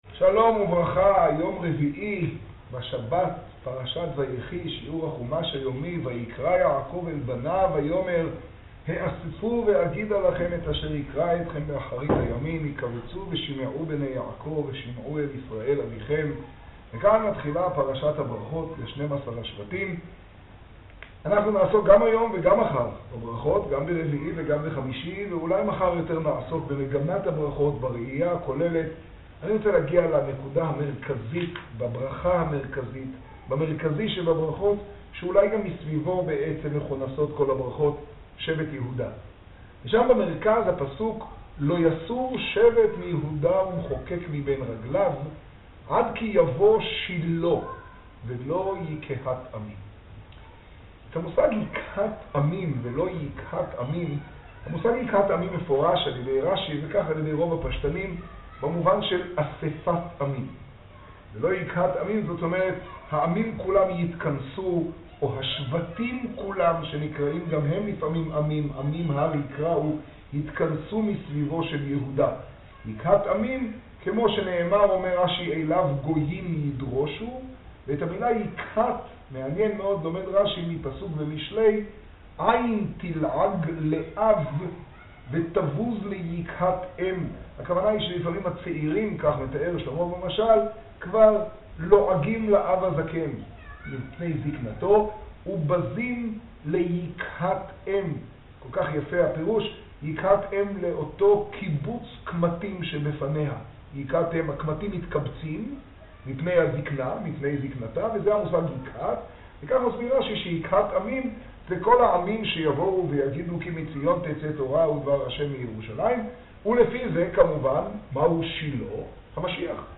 האזנה קטגוריה: מגדל - היום בפרשה , שיעור , תוכן תג: בראשית , ויחי , חומש , תשעה → רמבם יומי – ט טבת תשעה עד כי יבא שילה, י' בטבת תשעה ←